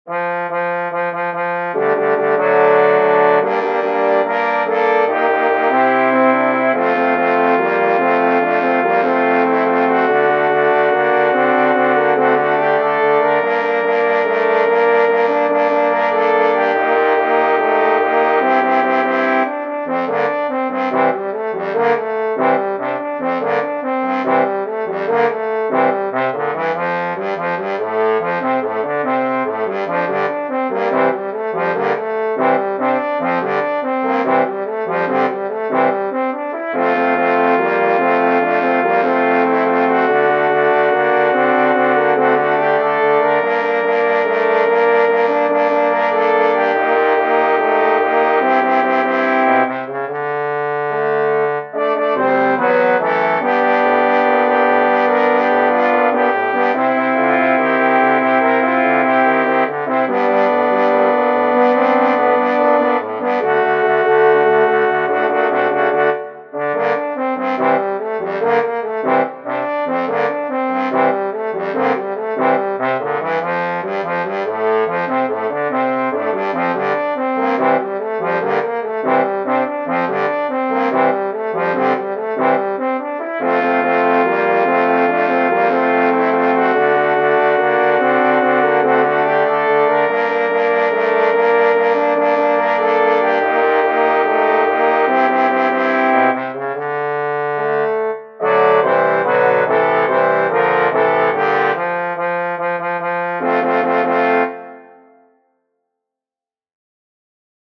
musescore audio